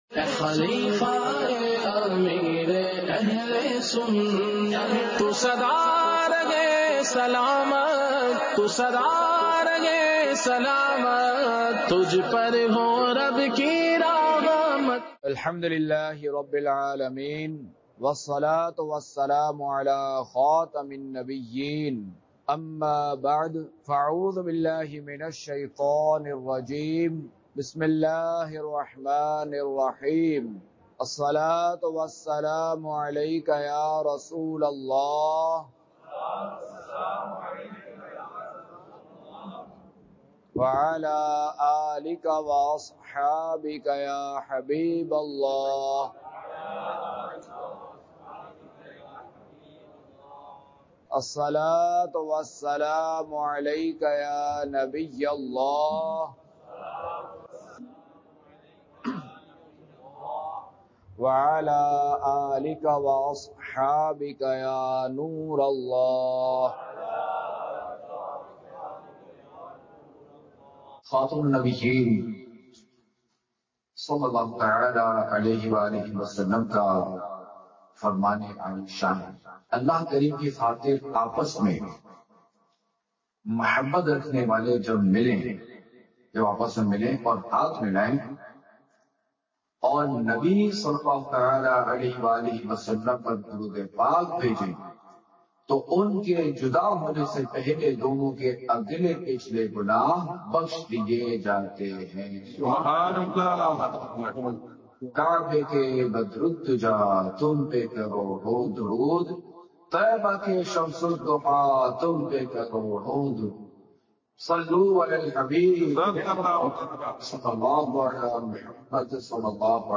بیانات